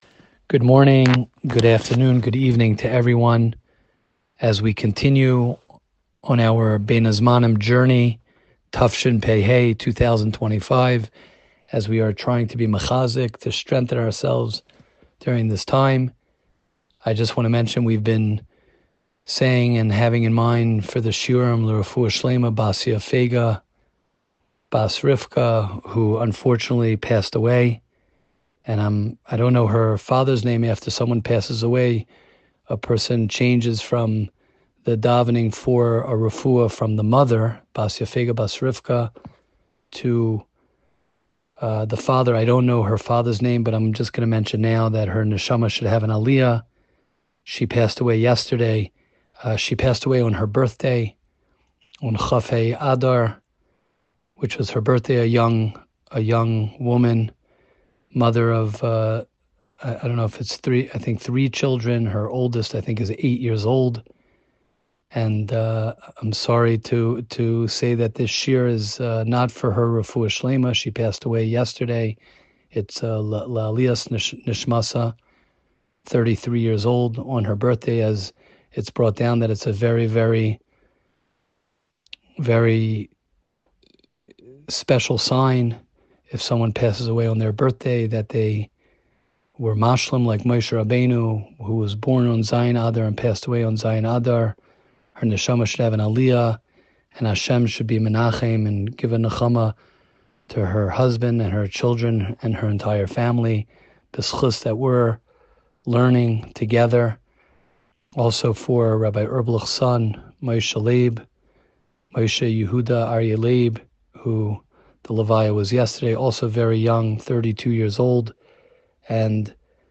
Bein Hazmanim Shiur